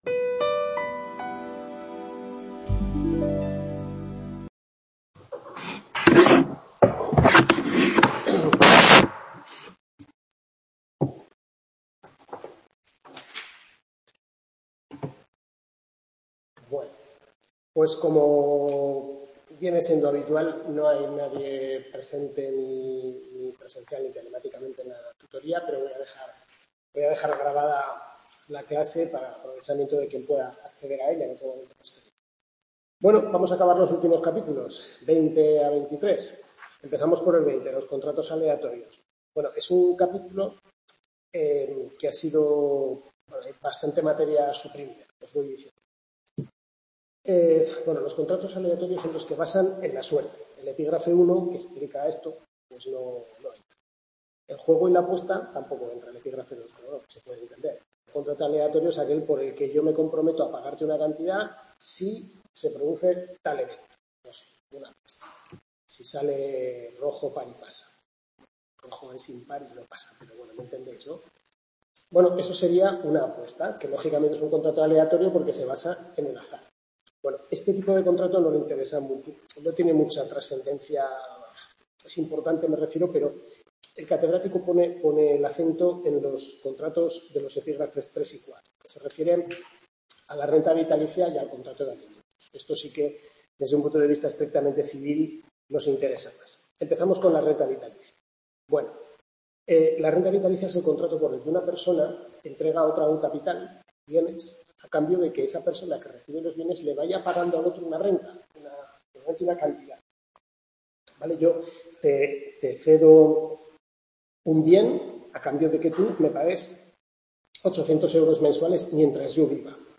Tutoría 6/6 Derecho de Contratos